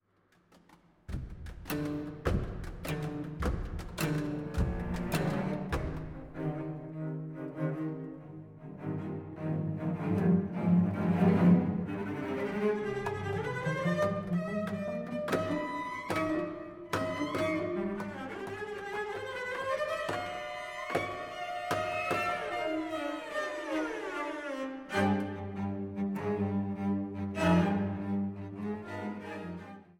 für 12 mafiotische Solocelli